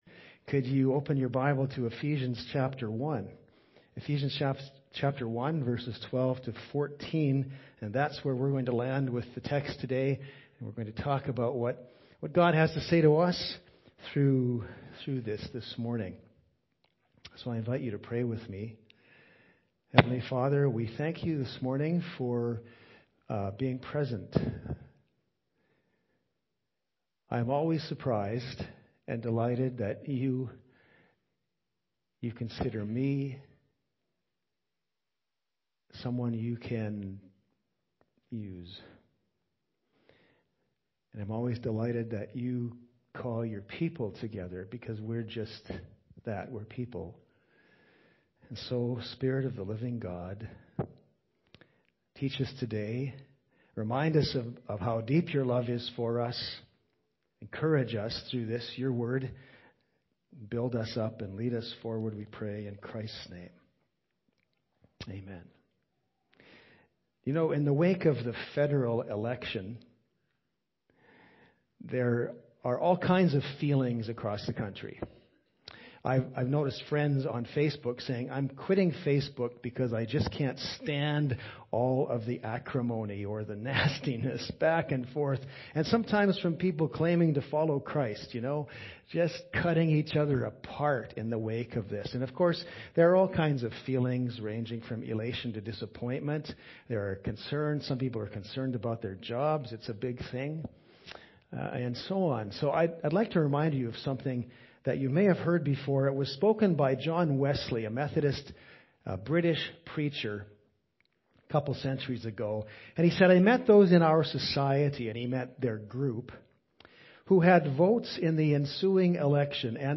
Teaching from Ephesians 1:12-14